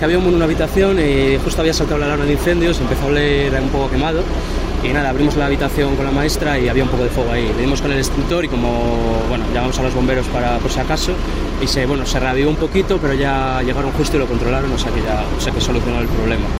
Estudiante, sobre el incendio en la residencia: Abrimos la habitación con la maestra y había fuego